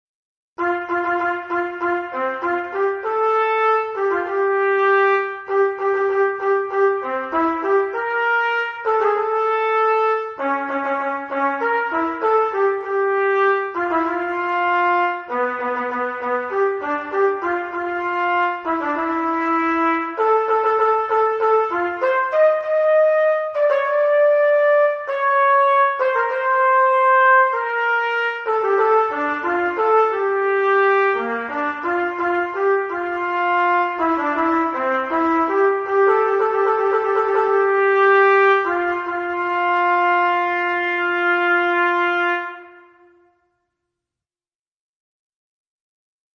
Hejnał już w sobotę